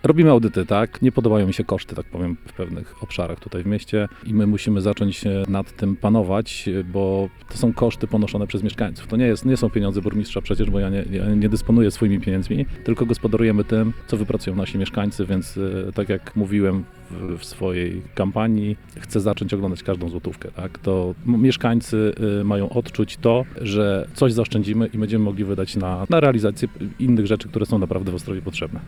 Burmistrz Betlejewski mówił, że pracy jest bardzo dużo i prosi mieszkańców o czas.